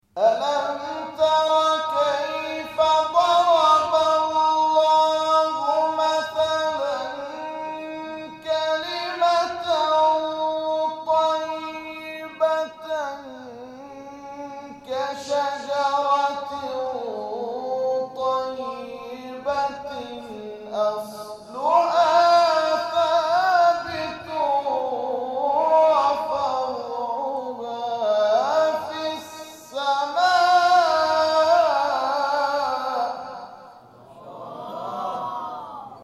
همچنین، سی امین کرسی تلاوت نفحات القرآن، پانزدهم دی ماه، در مسجد شهابی
در ادامه قطعات تلاوت این کرسی های تلاوت ارائه می شود.